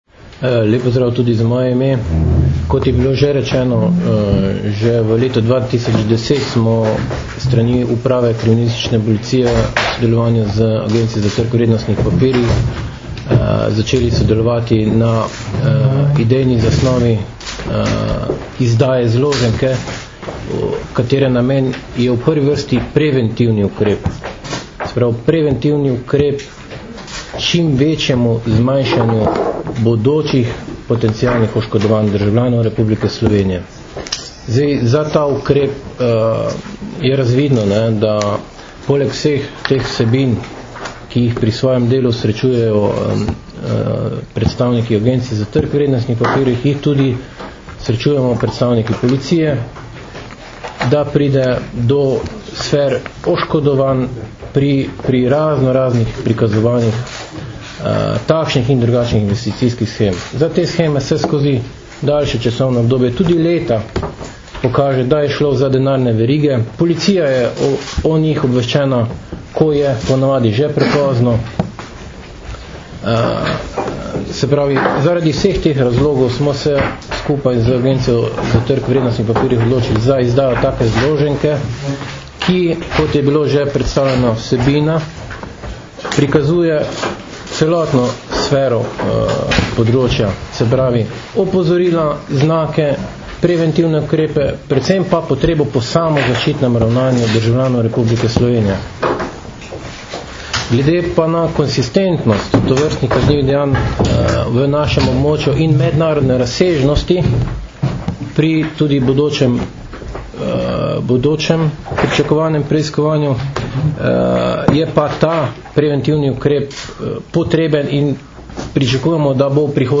Policija in ATVP izdali zloženko o denarnih verigah - informacija z novinarske konference
Zvočni posnetek izjave